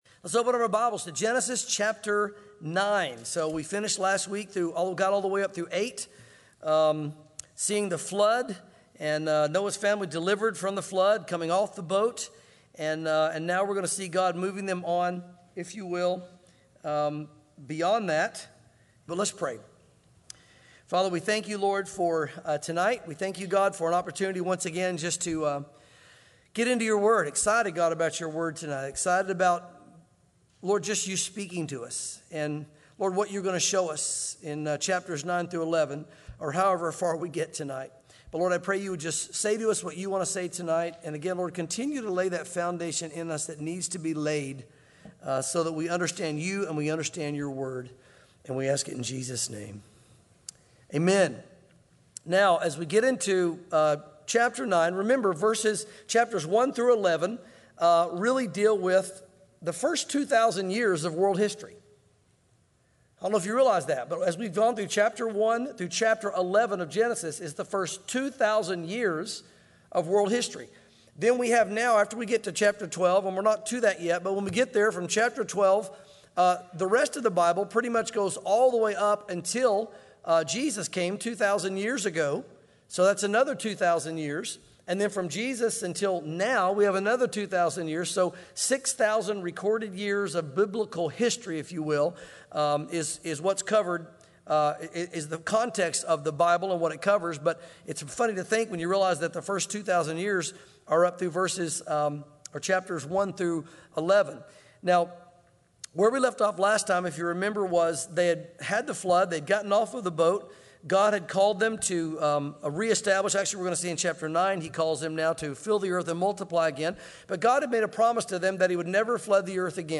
sermons
Calvary Chapel Knoxville